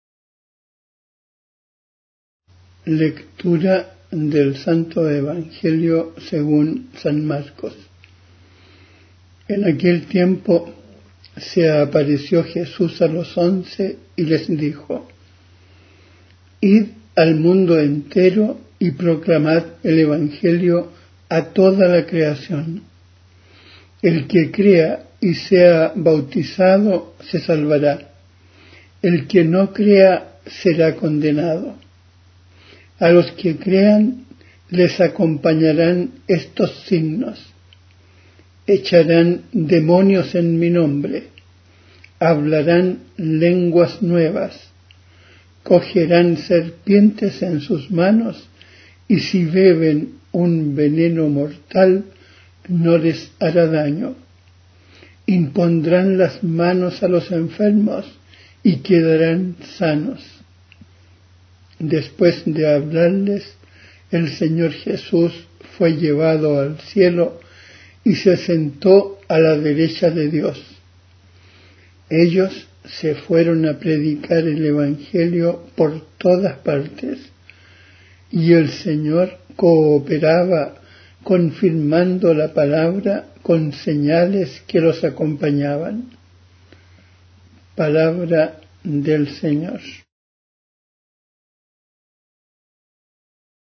Evangelio en audio.